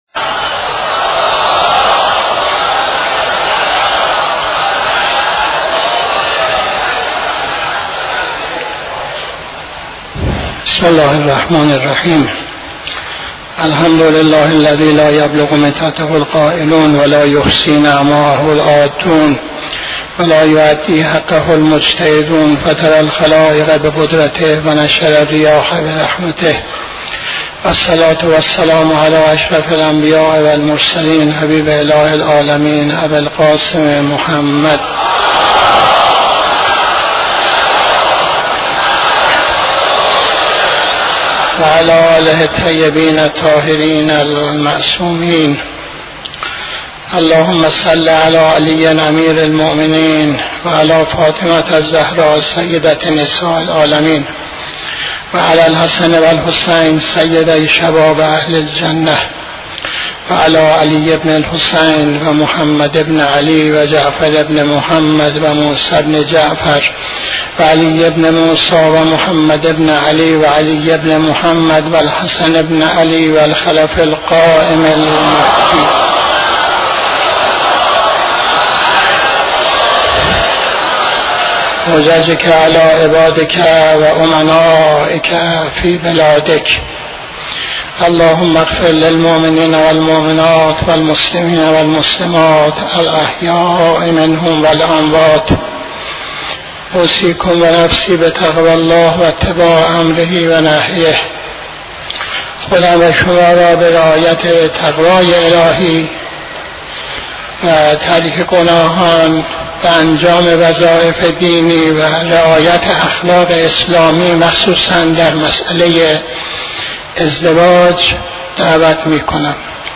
خطبه دوم نماز جمعه 27-06-83